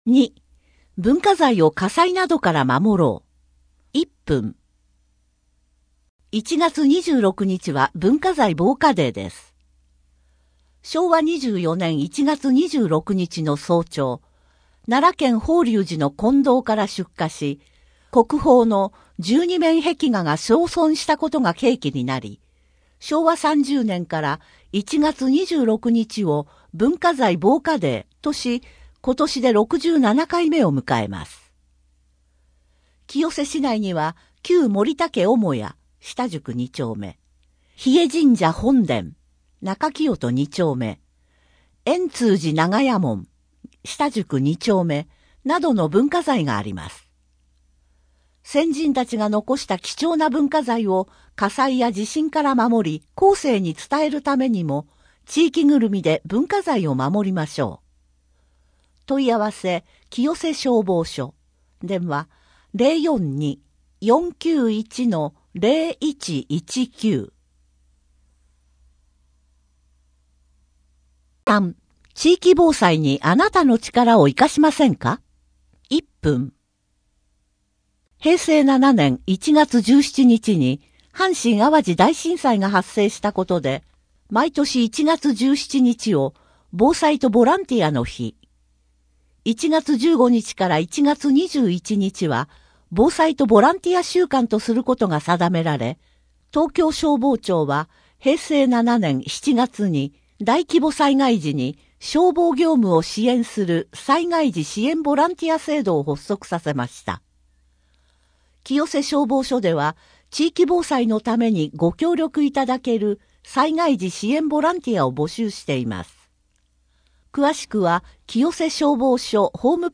ヤシャ・モンク著 野塩図書館特別整理休館のお知らせ まちかどニュース 中里の火の見やぐらを撤去しました 清瀬けやきホール・コミュニティプラザひまわりの催し物 人口と世帯 声の広報 声の広報は清瀬市公共刊行物音訳機関が制作しています。